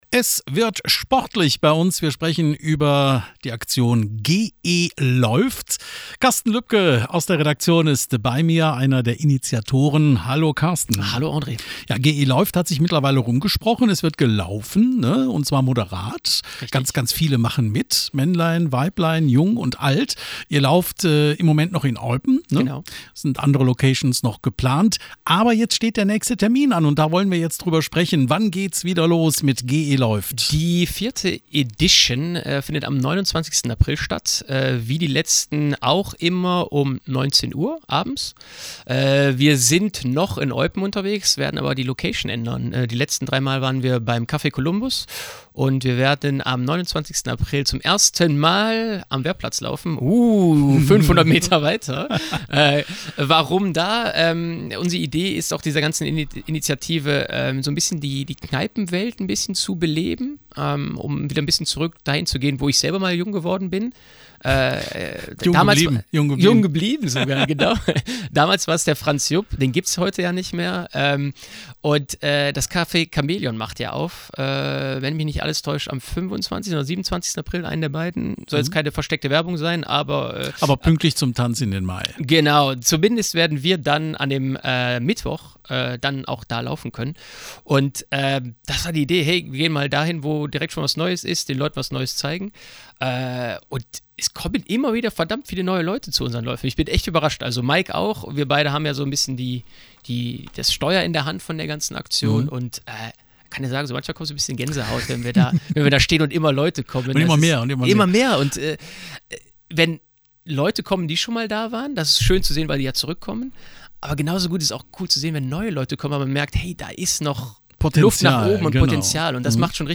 GElaeuft-Interview-April-2026.mp3